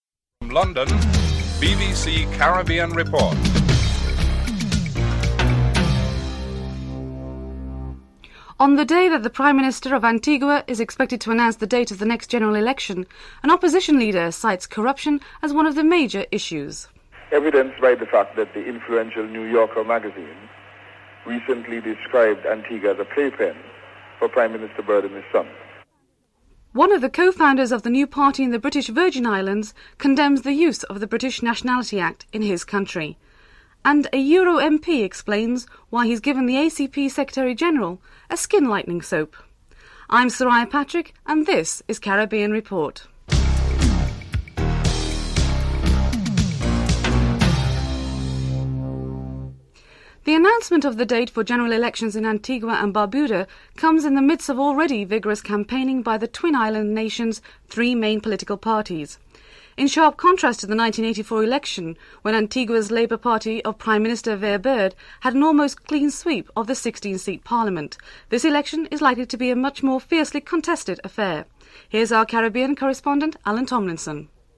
1. Headlines (00:00-00:50)
2. Interview with Tim Hector, Leader of the Oppostion and party, Antigua Caribbean Liberation Movement on his strategies to win the elections (00:51-07:10)
3. Financial News (07:11-08:45)